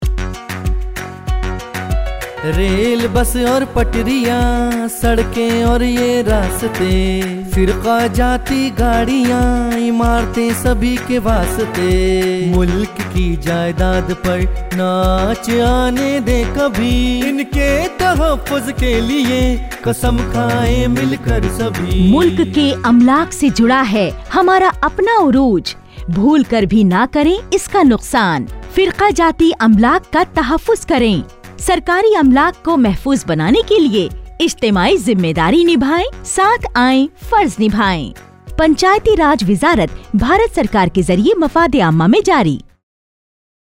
242 Fundamental Duty 9th Fundamental Duty Safeguard public property Radio Jingle Urdu